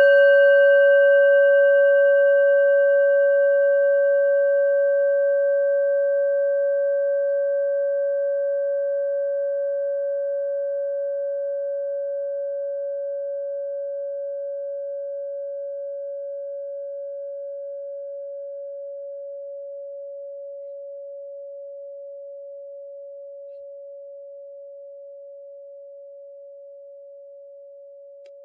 Kleine Klangschale Nr.26
Sie ist neu und ist gezielt nach altem 7-Metalle-Rezept in Handarbeit gezogen und gehämmert worden.
Die Frequenz des Plutotons liegt bei 140,25 Hz und dessen tieferen und höheren Oktaven. In unserer Tonleiter liegt er nahe beim "Cis".